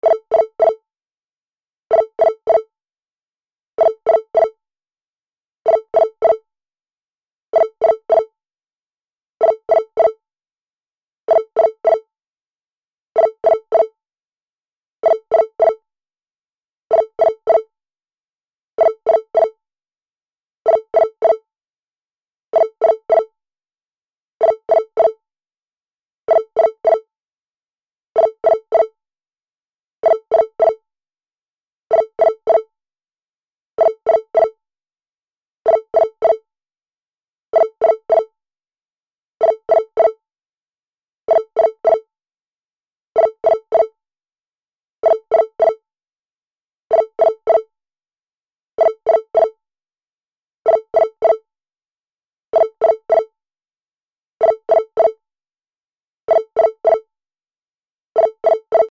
/ G｜音を出すもの / G-01 機器_電話
電話 内線呼び出し 着信音1
電子音 オフィスの電話などに